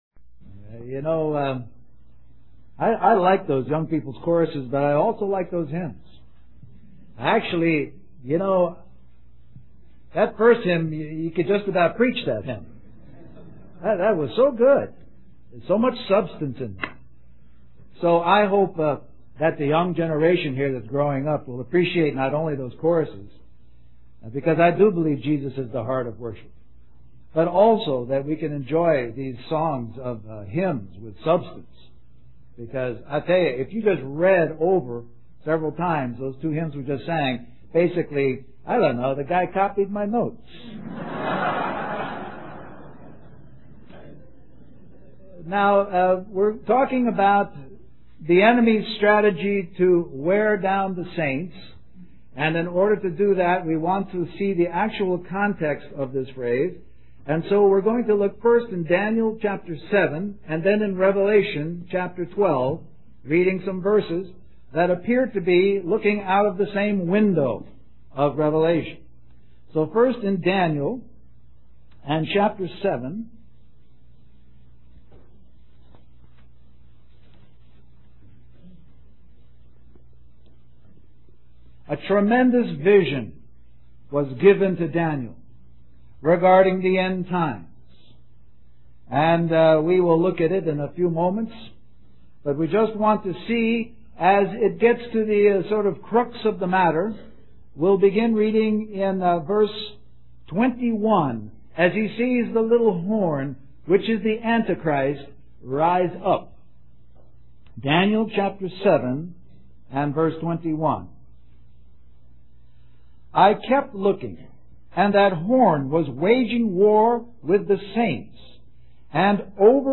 A collection of Christ focused messages published by the Christian Testimony Ministry in Richmond, VA.
Harvey Cedars Conference